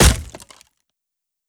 Hit_Wood 02.wav